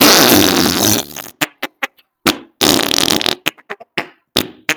Very Fast Farts Sound Button: Unblocked Meme Soundboard